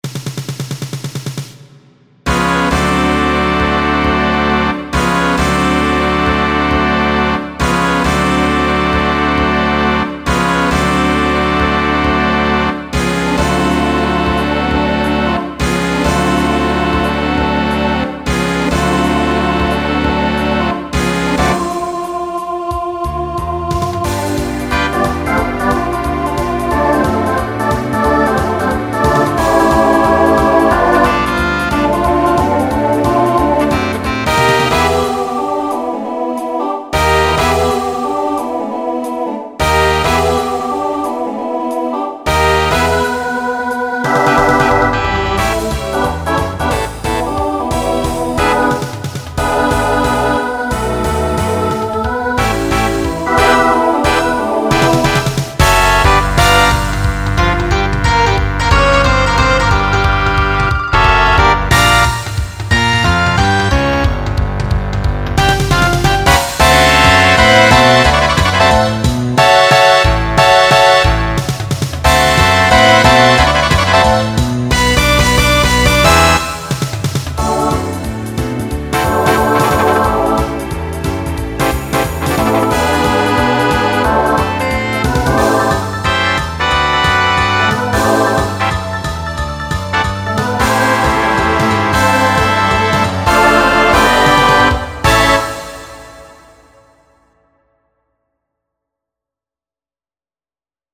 Transition Voicing SATB